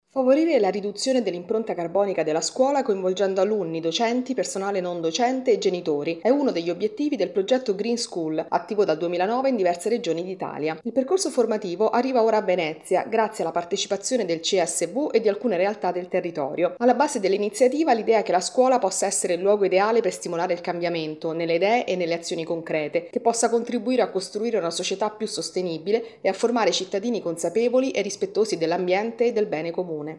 A Venezia il programma per favorire pratiche di sostenibilità ambientale nelle scuole. Il servizio